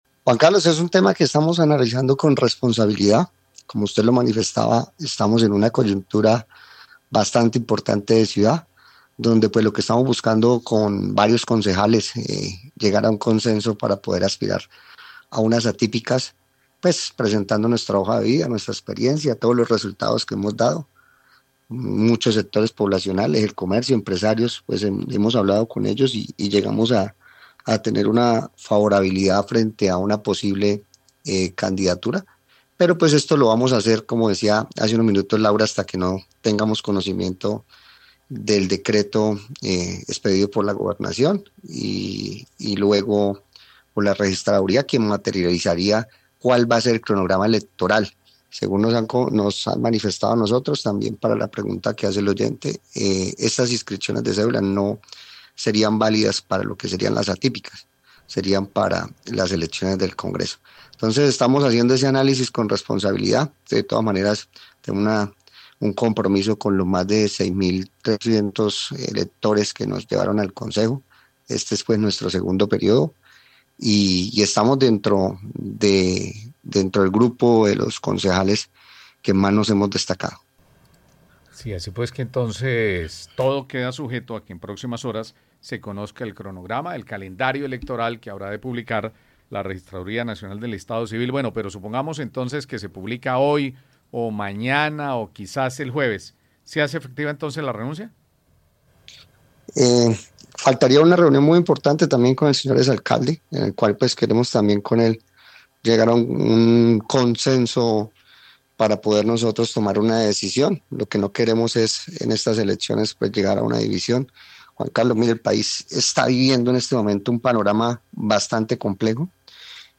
En una entrevista con Caracol Radio, el concejal de Cambio Radical dijo que aún no renuncia, pero que sí tiene intenciones de candidatizarse a la alcaldía.